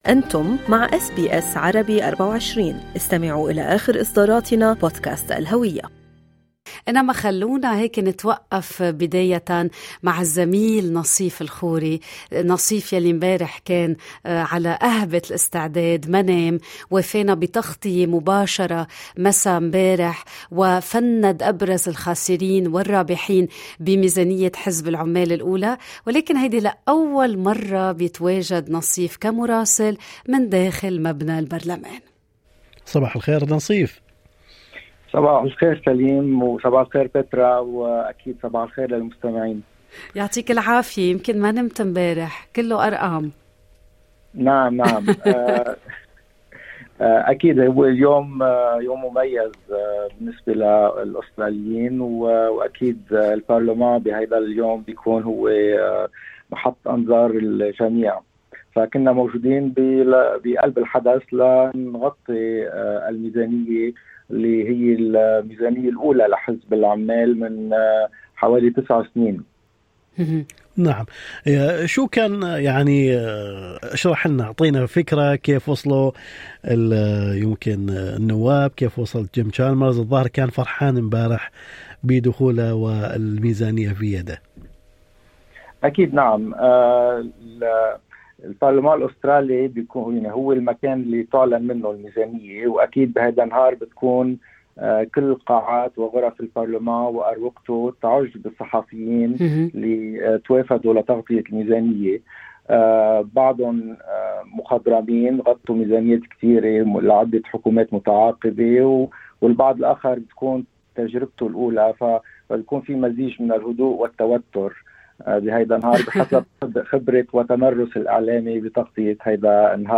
مقابلة خاصة